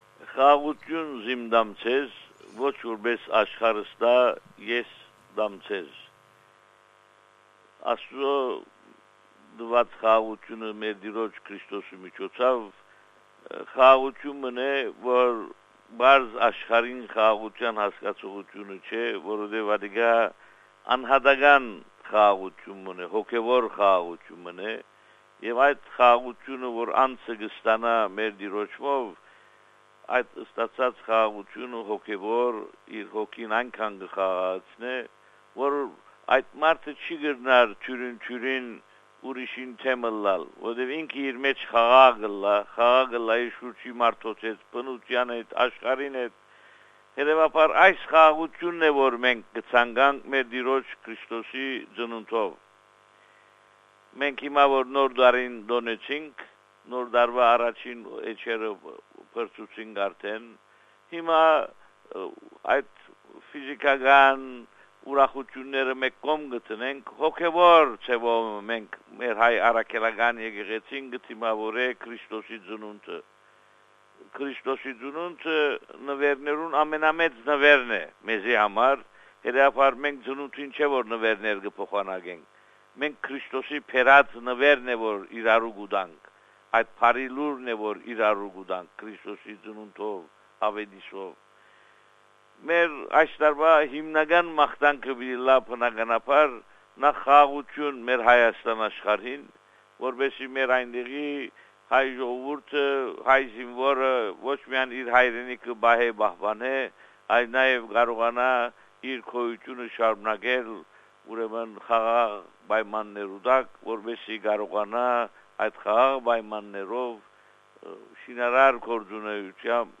New Year & Christmas message by the Primate of Armenians in Australia & New Zealand, Bishop Haygazoun Najarian.